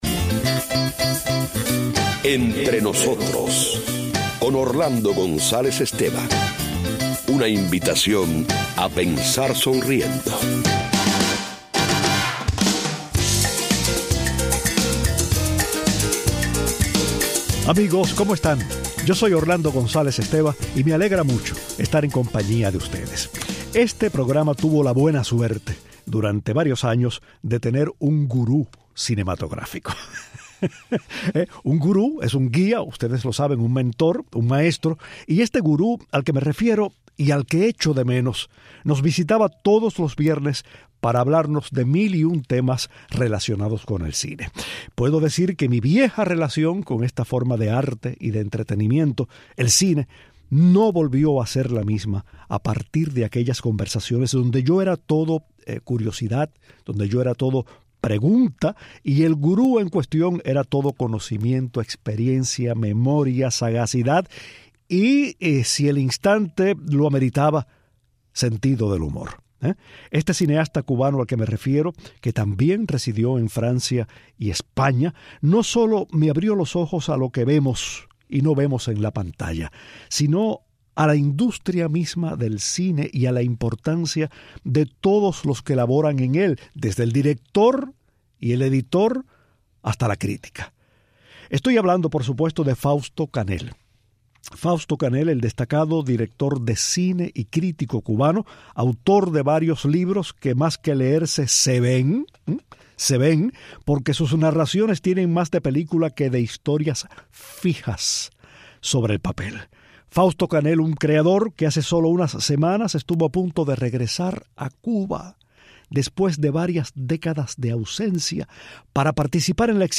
El destacado cineasta y crítico de cine cubano habla de su obra, de la omnipresencia de la imagen en la vida actual, su frustrado regreso a La Habana y su inalterable pasión por el cine